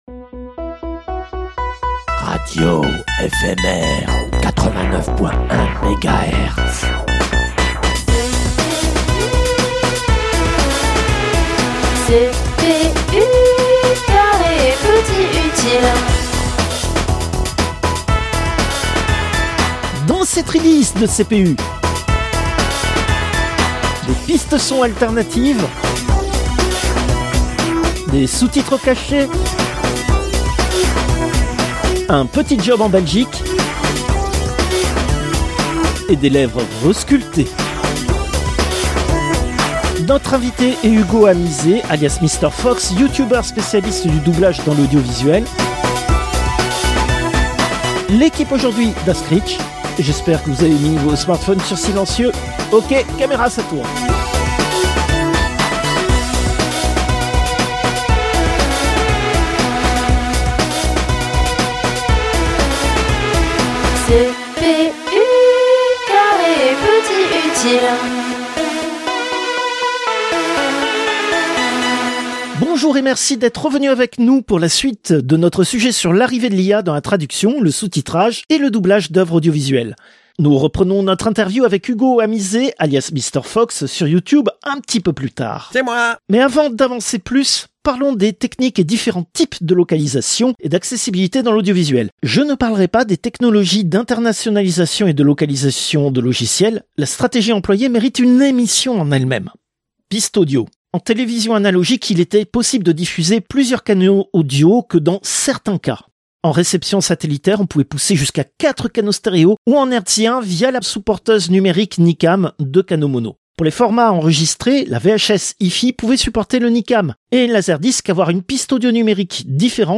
Interview, troisième partie
Interview, quatrième partie